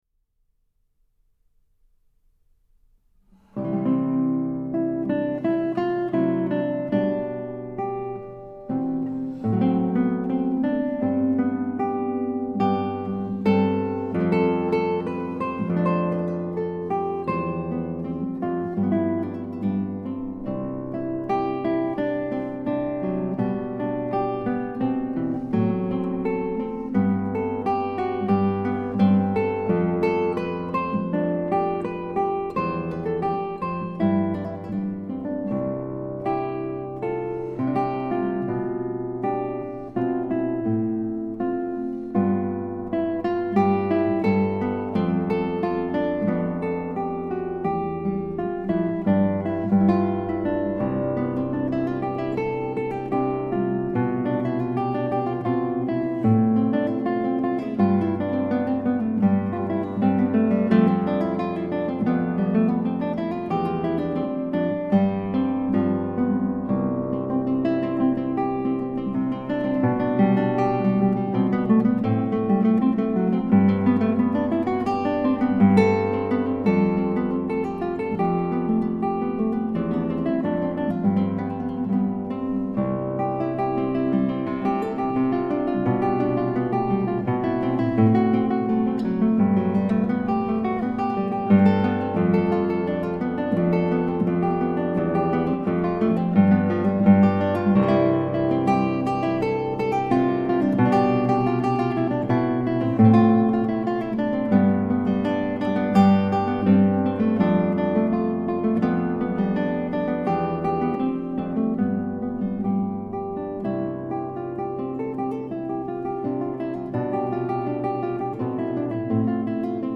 一张演奏十分写意舒情的巴洛克时期的吉他音乐。
音色的变化比起六条弦线的吉他所弹奏出来的音域更广, 音色变化更显得丰富。